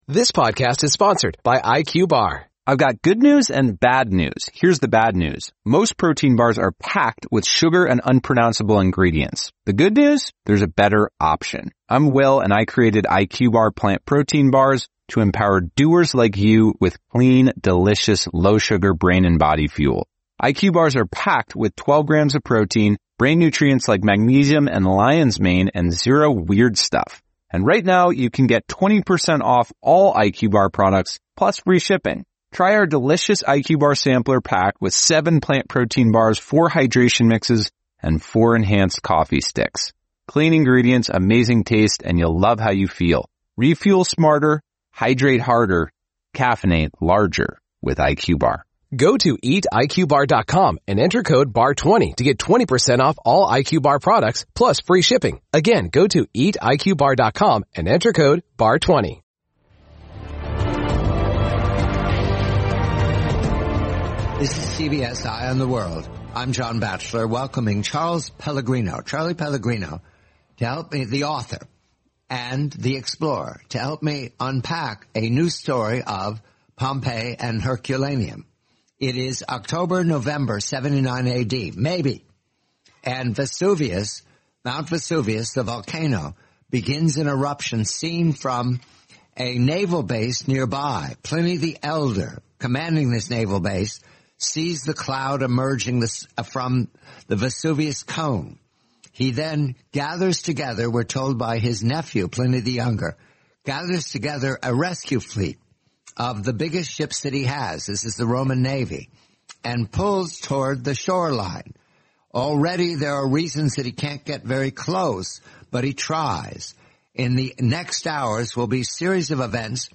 the complete, twenty-minute interview